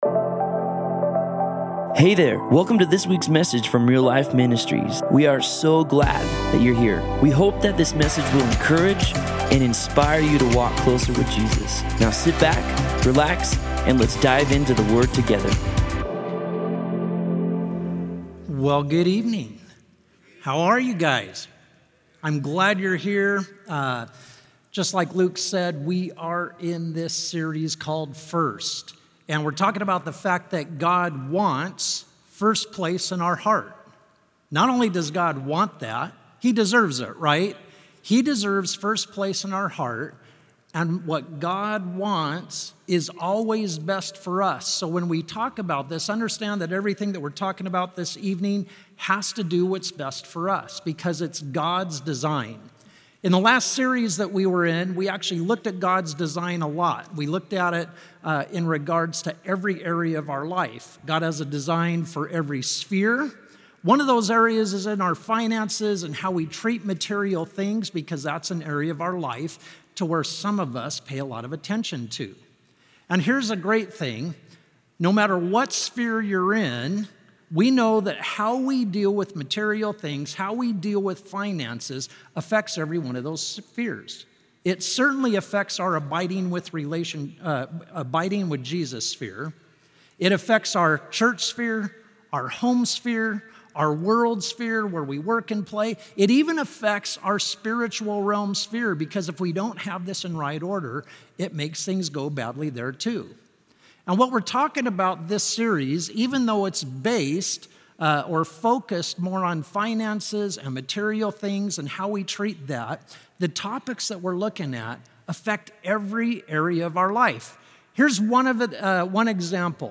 First+Week+2_Sun+Evening.mp3